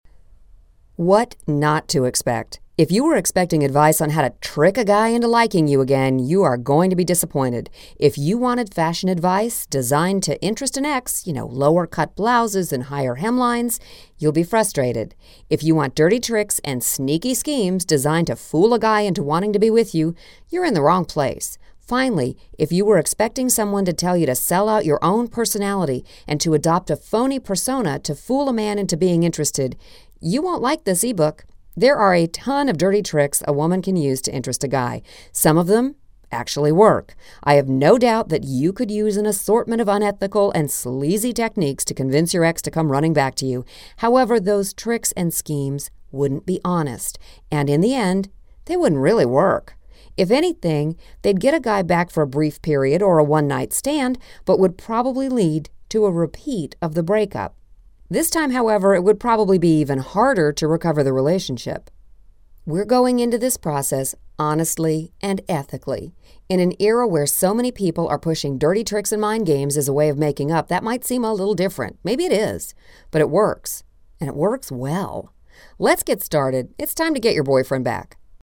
This audio book is overflowing with awesome information, great tips and MORE!